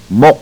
To hear proper pronunciation, click one of the links below
Mohk - Neck